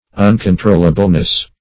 [1913 Webster] -- Un`con*trol"la*ble*ness, n. --
uncontrollableness.mp3